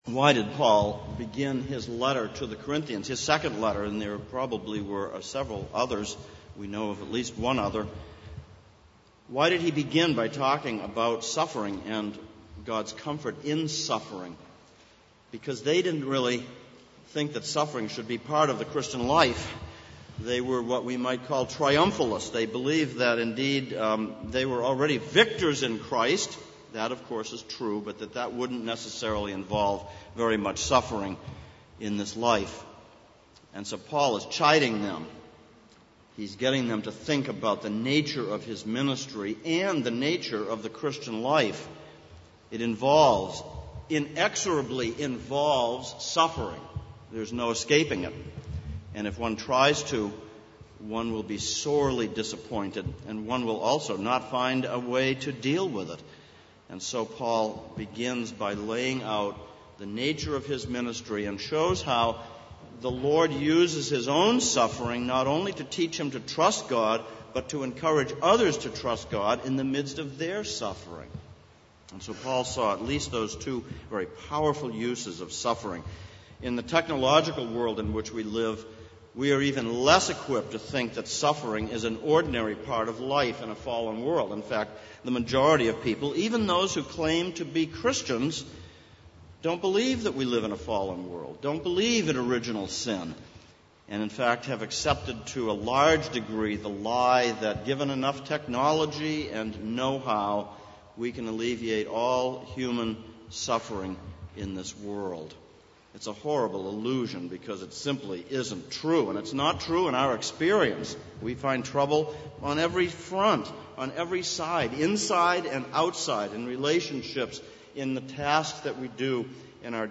Exposition of Psalms Passage: Psalm 55:1-23 Service Type: Sunday Morning « 31.